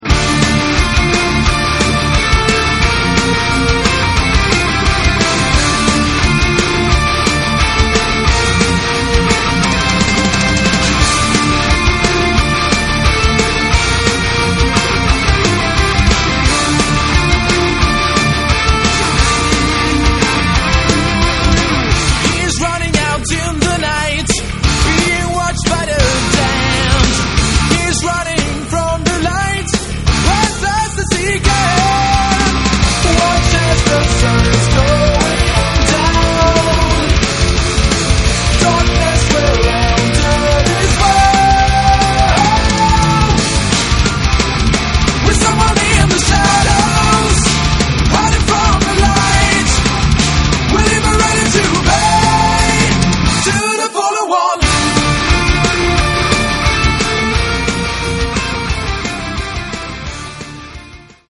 гитары
вокал, клавишные
ударные
бас